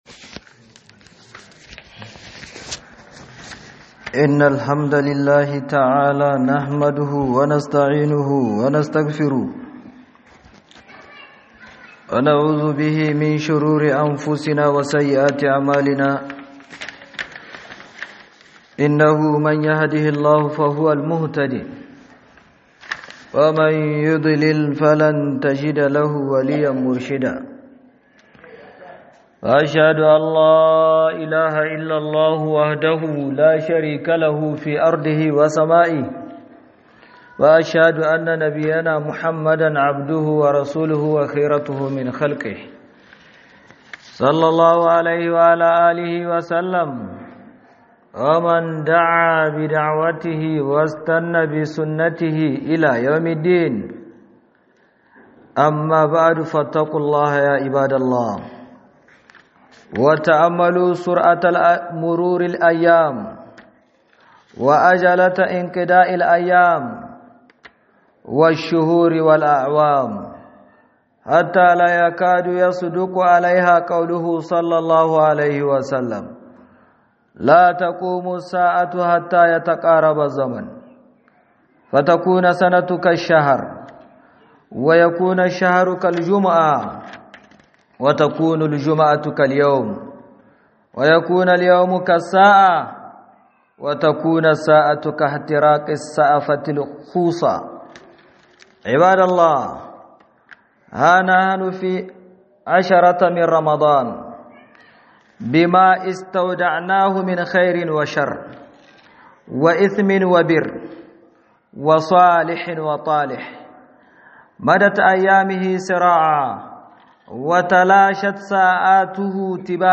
2026-02-27_HUDUBA KA LURA DA SAURIN YANAYI' - HUDUBA